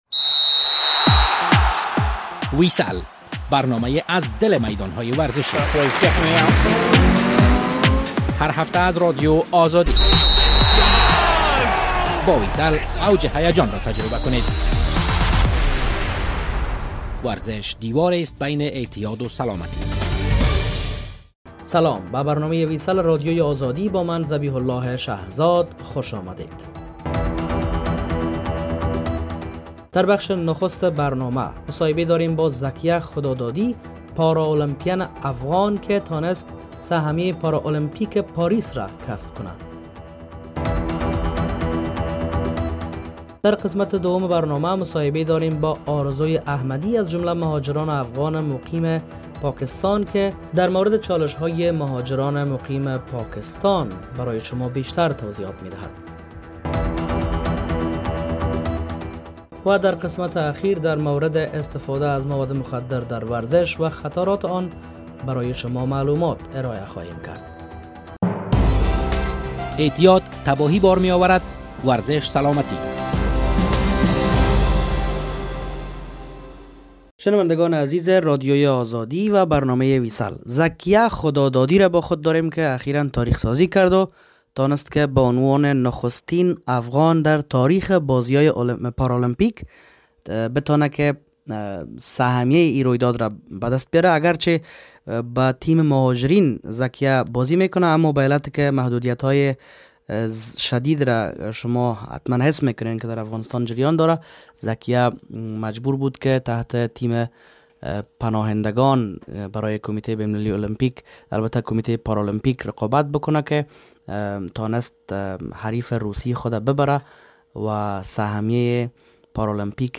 مصاحبه انجام شده است